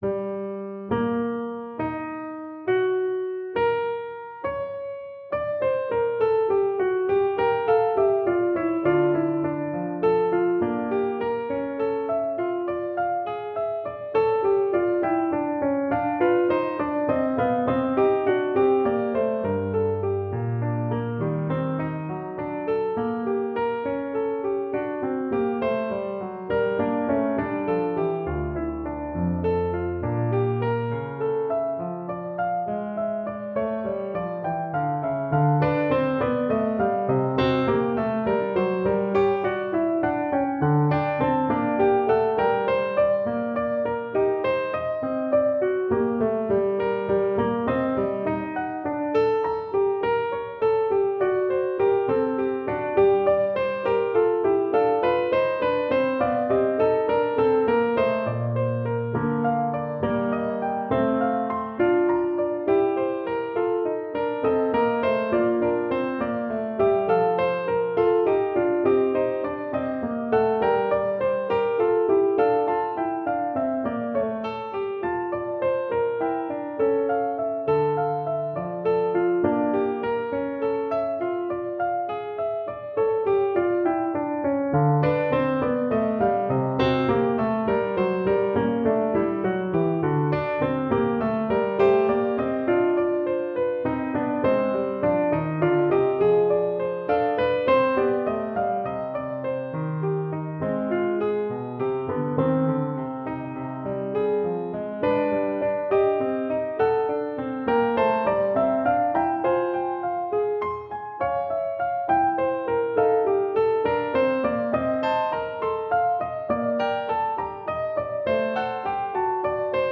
for String Trio